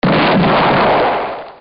Click_ztlyin.mp3